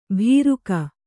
♪ bhīruka